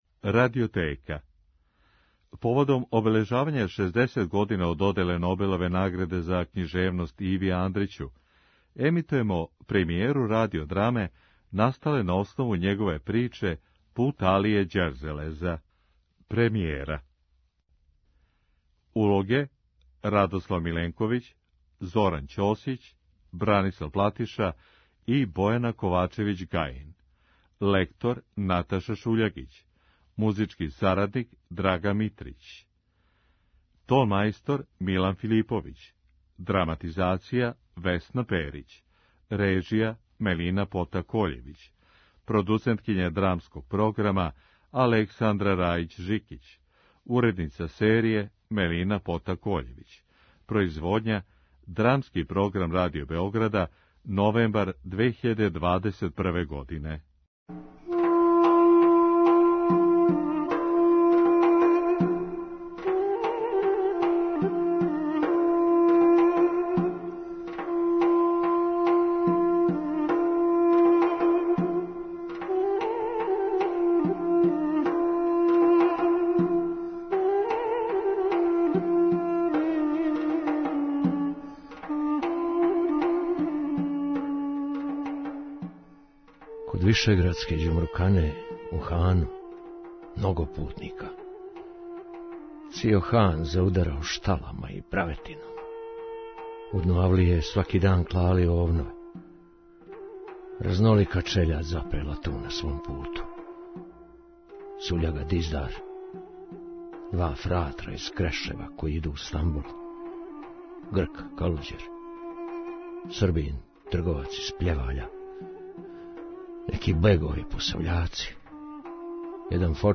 Драма је снимљена поводом обележавања 60 година од доделе Нобелове награде Иви Андрићу.